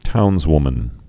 (tounzwmən)